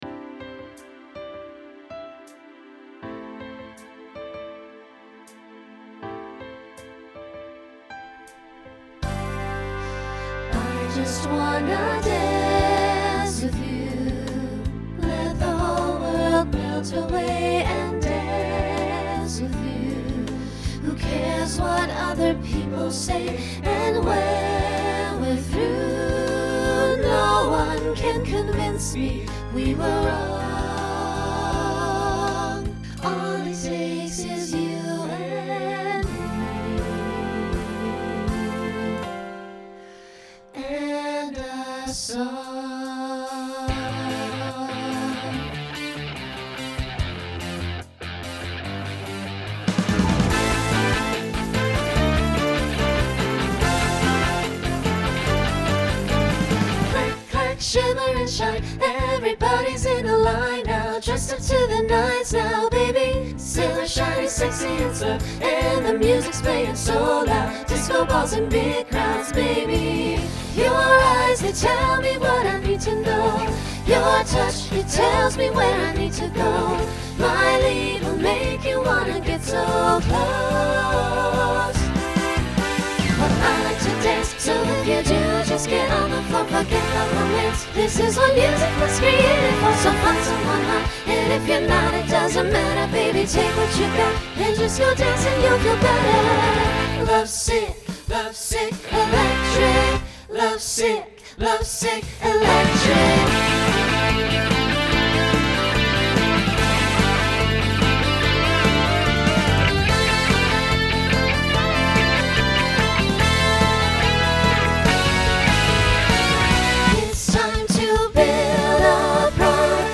Genre Broadway/Film , Rock
Story/Theme Voicing SATB